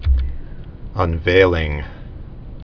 (ŭn-vālĭng)